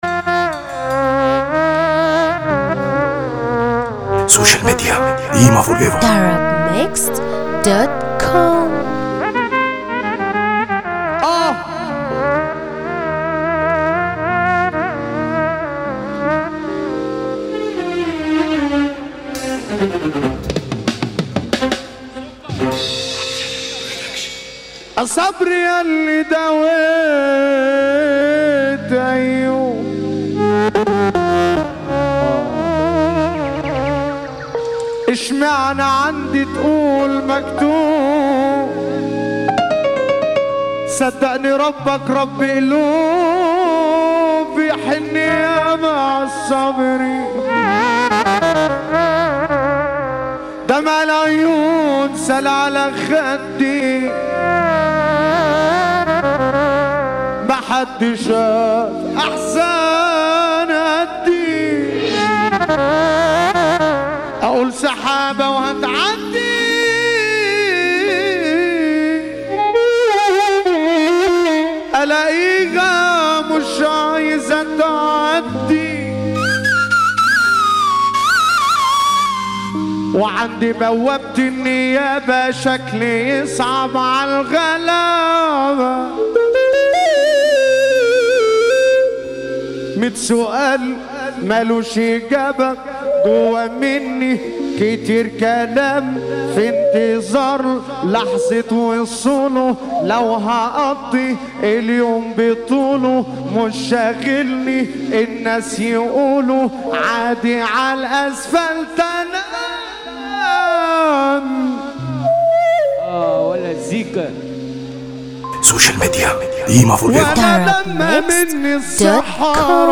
موال
حزينا جدا